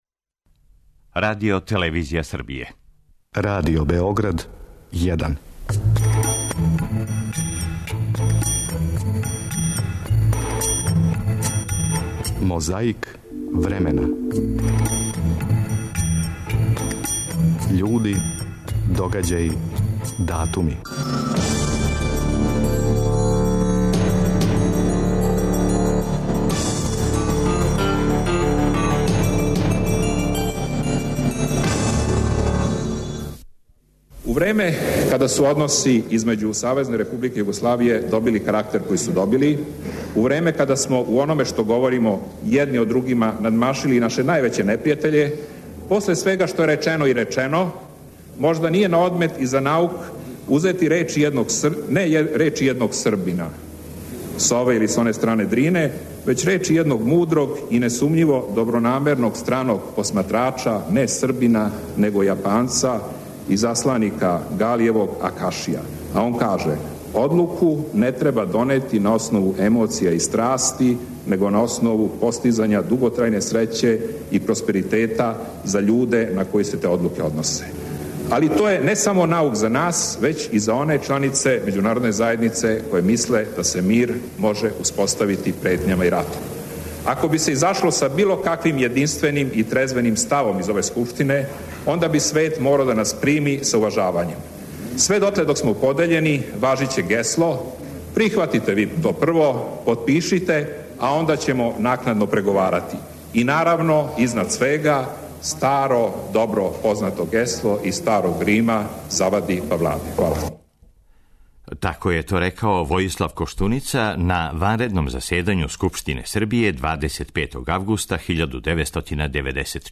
Крећемо од Војислава Коштунице, а говорио је на ванредном заседању Скупштине Србије, 25. августа 1994. године.
А када су 25.августа 1944. године савезничке снаге ушле у град светлости, са једног балкона, Парижанима и њиховим ослободиоцима, говорио је генерал Шарл де Гол. 25. августа 1994. године, на ванредном заседању Скупштине Србије вођена је расправа о предлогу мировног плана контакт групе за окончање рата у Босни.
Тим поводом слушамо део његовог говора у Косову пољу 1986., у време када је био председник председништва СР Србије.